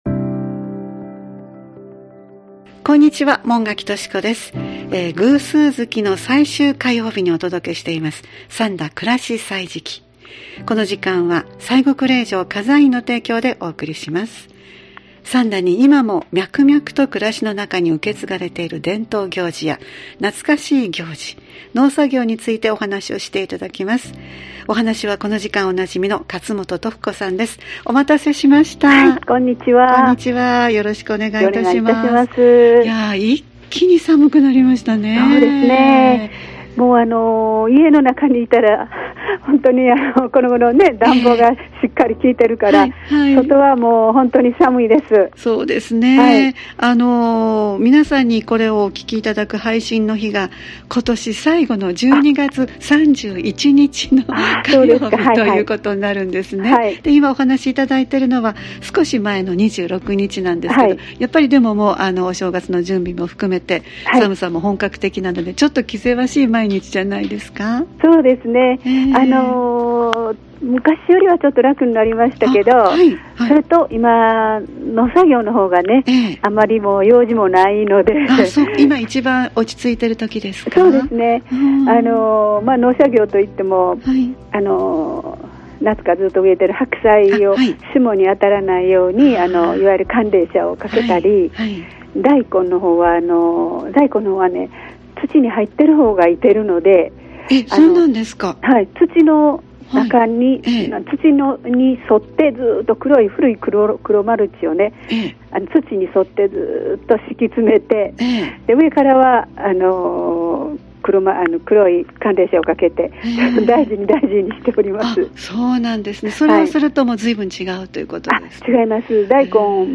今回もお電話でこの時期の農作業とお正月の準備についてお話しいただきました。